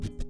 Scratch Rnb.wav